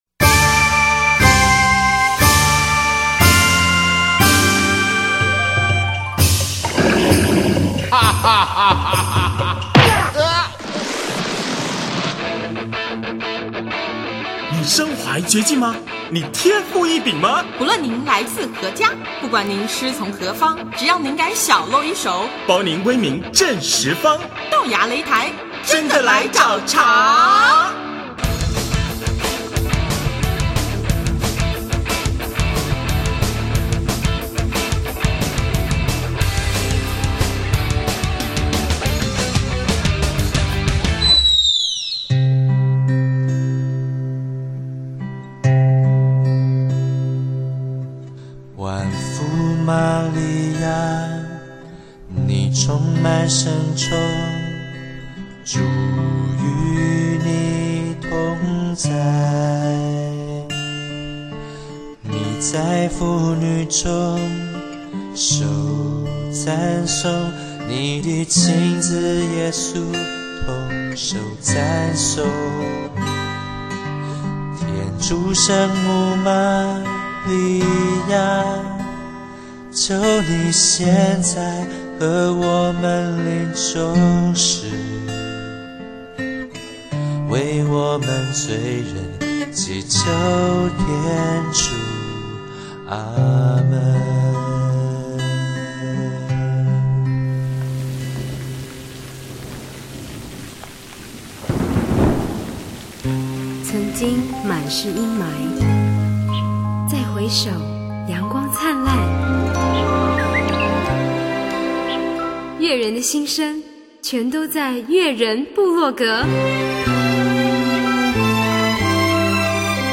就让我们怀着感恩之心并随着悠扬的乐曲，一起品尝天主所赐的美味神粮吧！